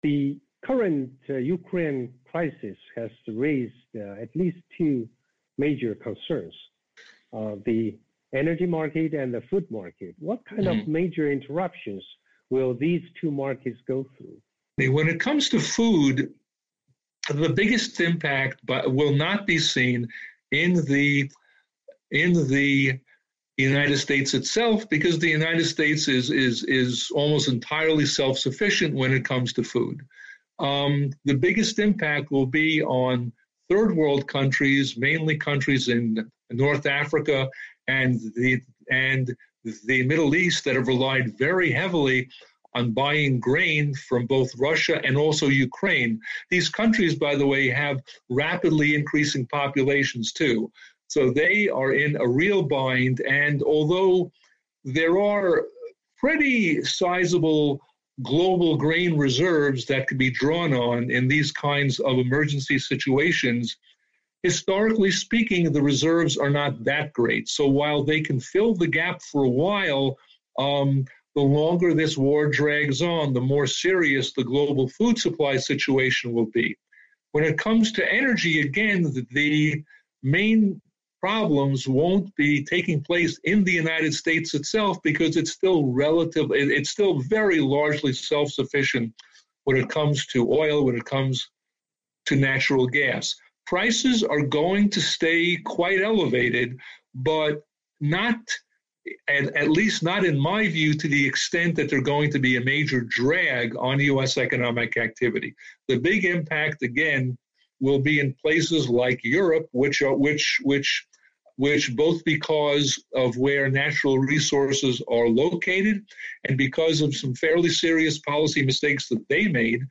VOA专访